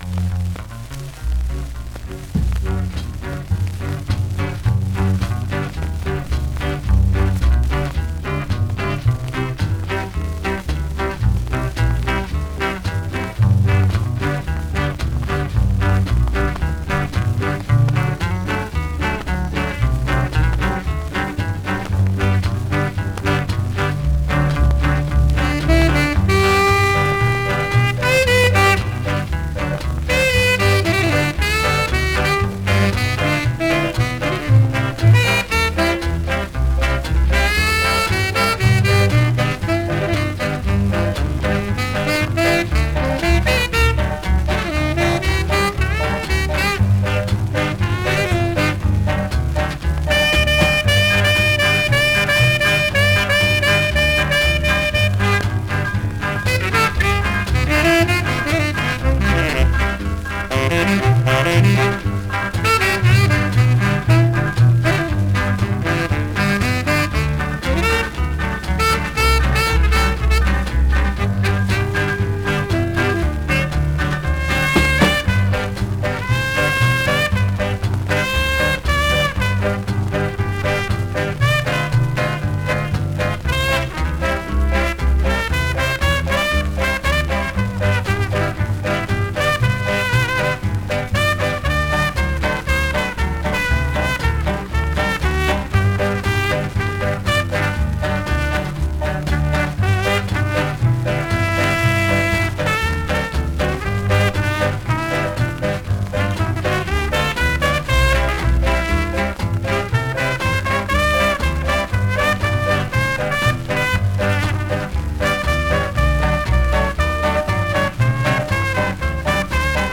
RETURN TO THE PRE-SKA / BOOGIE / SHUFFLE PAGE